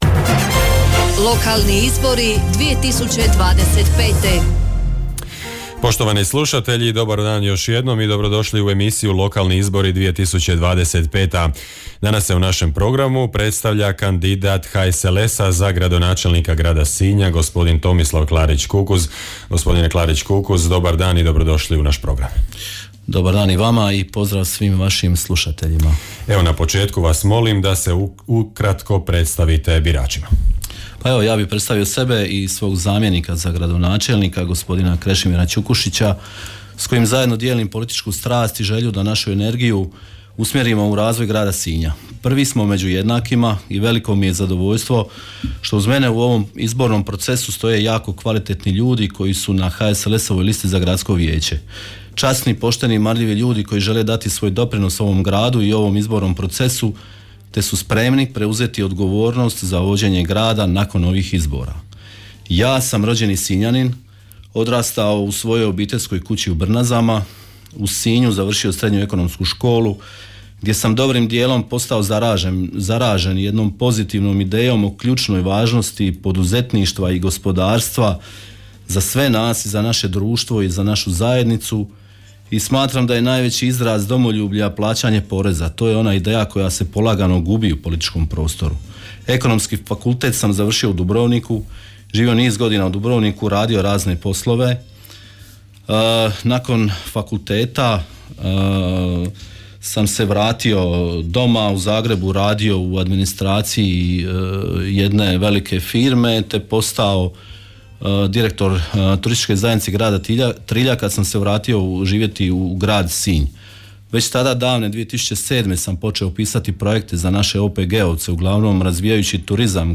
Hit radio prati izbore u 7 jedinica lokalne samouprave (Grad Vrlika, Općina Dicmo, Općina Hrvace, Općina Dugopolje, Općina Otok, Grad Trilj, Grad Sinj). Sve kandidacijske liste i svi kandidati za načelnike odnosno gradonačelnike tijekom službene izborne kampanje imaju pravo na besplatnu emisiju u trajanju do 10 minuta u studiju Hit radija.